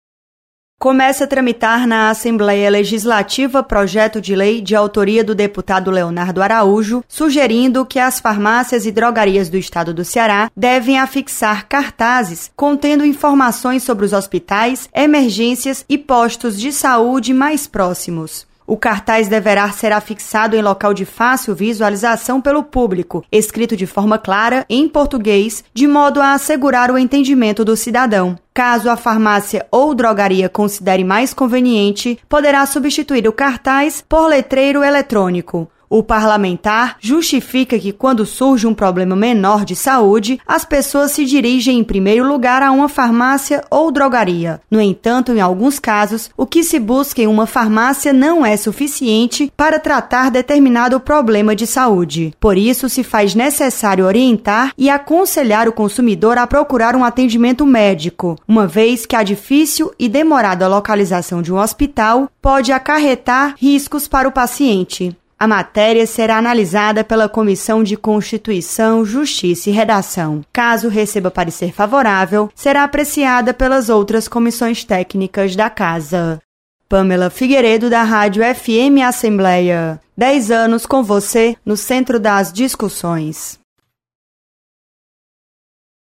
Projeto obriga afixação de cartazes em farmácias orientando sobre hospitais mais próximos. Repórter